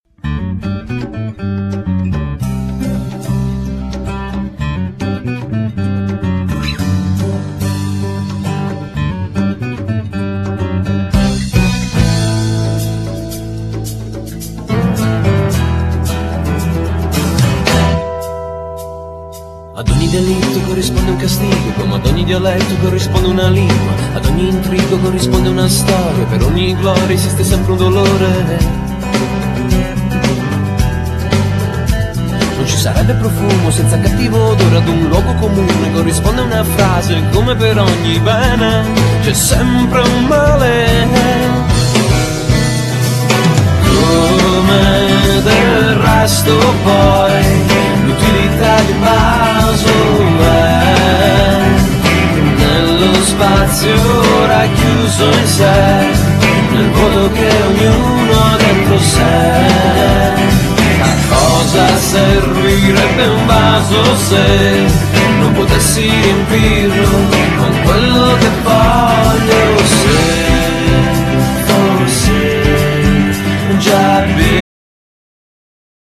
Genere : Pop/ rock